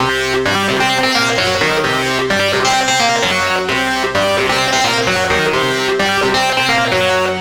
CRYINGBRASSA 1.wav